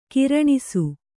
♪ kiraṇisu